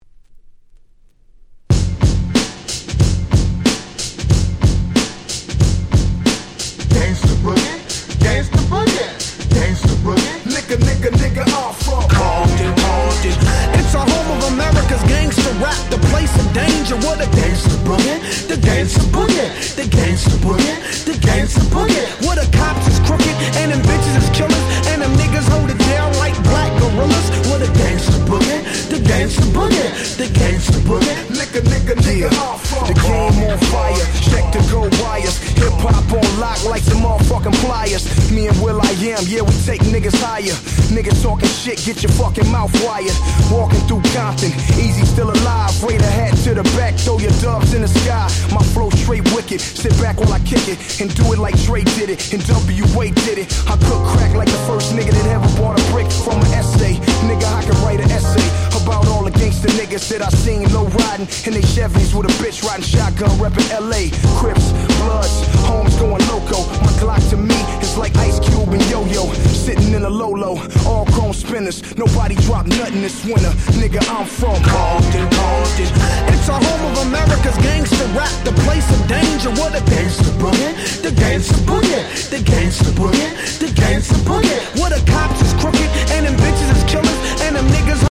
06' Smash Hit Hip Hop !!
G-Rap Gangsta Rap